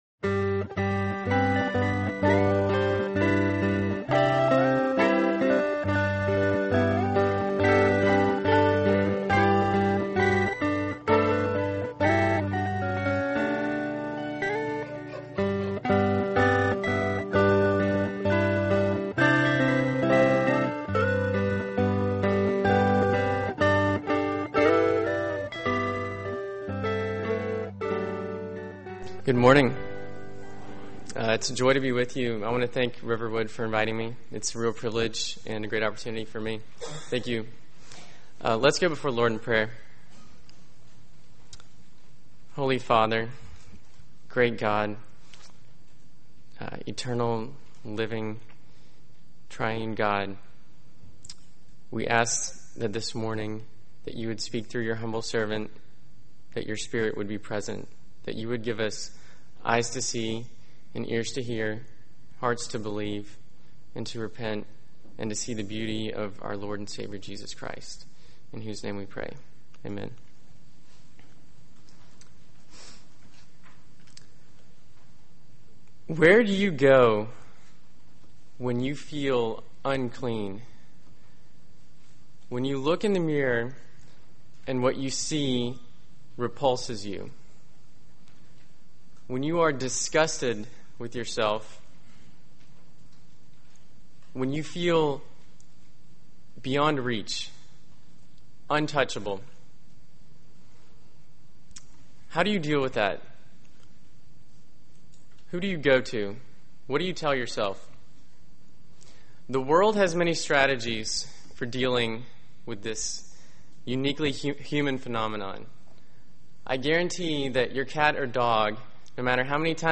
Sermon on Mark 5:24-34 from July 4, 2010